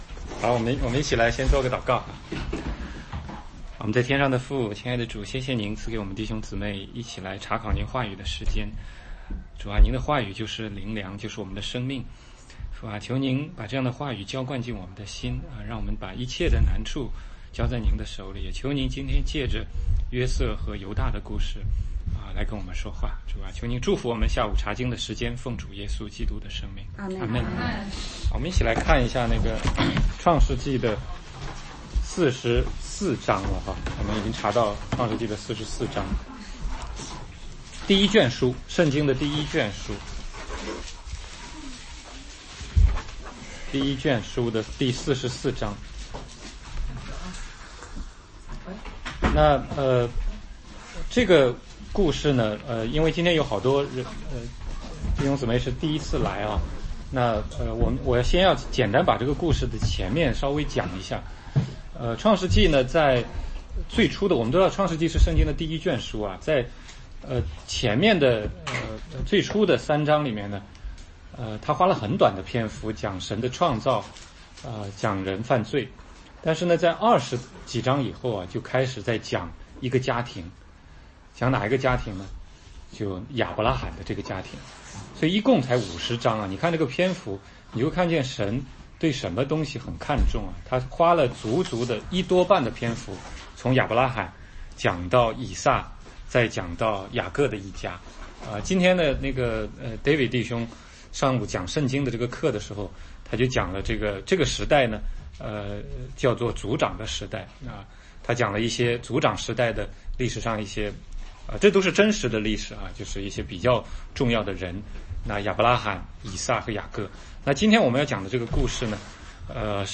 16街讲道录音 - 创世纪44, 45:1-5